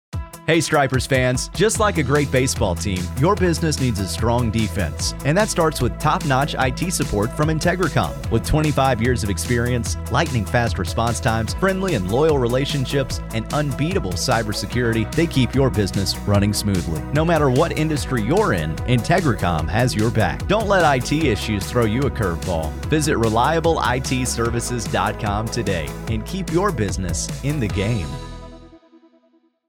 IntegriCom Radio Spot
Stripers-radio-ad.mp3